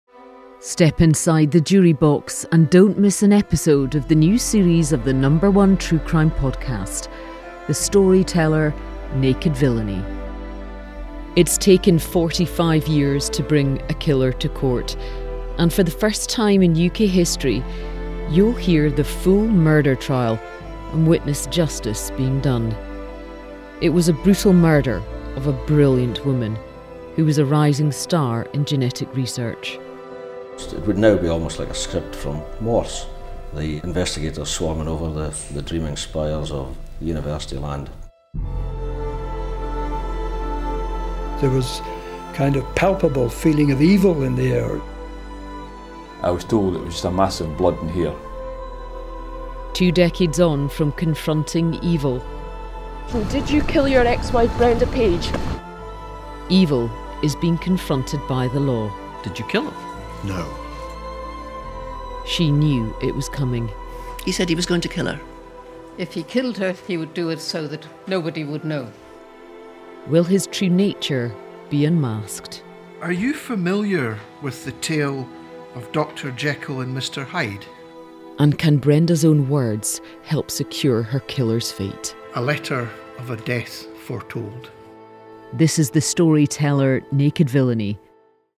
A teaser of a mammoth series which is making history. This is the first time in UK history that a murder trial has been recorded in full.